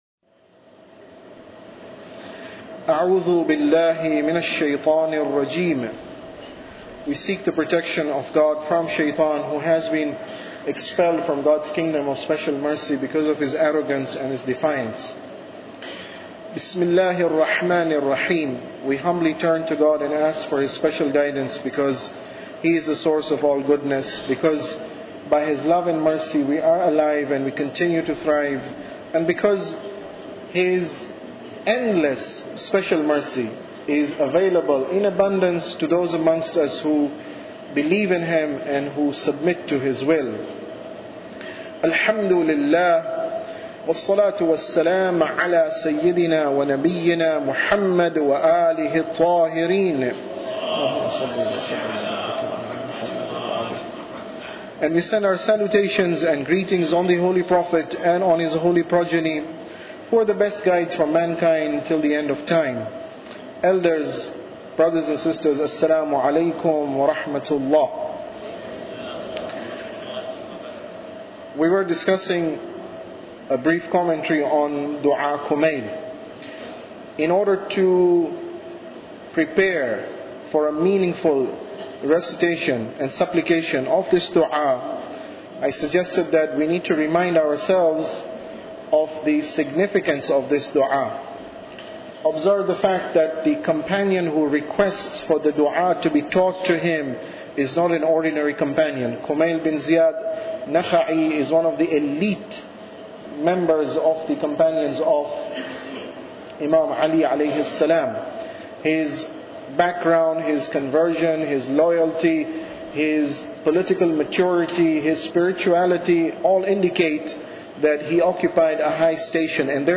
Tafsir Dua Kumail Lecture 15